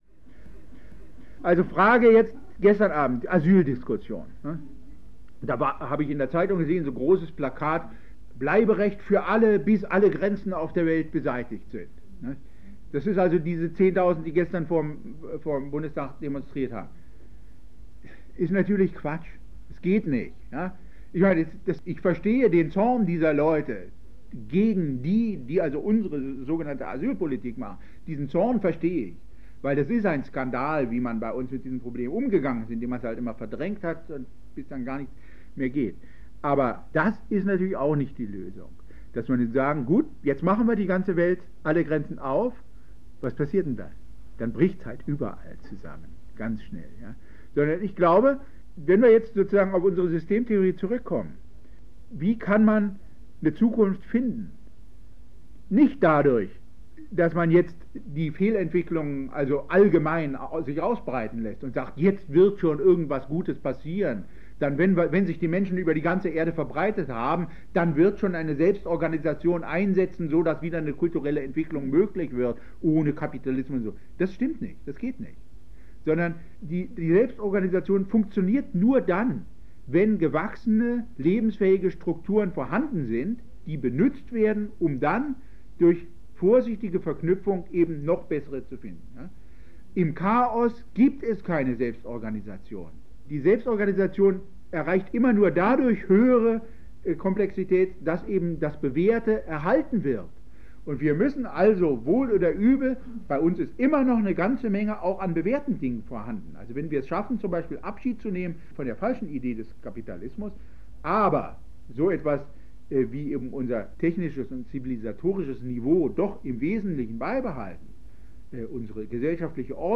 Peter Kafka – Vortrag in München 1992
Auf Einladung der Volkshochschule München – Zweigstelle Pasing – hielt Peter Kafka 1992 einen Vortrag für deren Dozent*innenkreis.